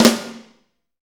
Index of /90_sSampleCDs/Northstar - Drumscapes Roland/DRM_Fast Shuffle/SNR_F_S Snares x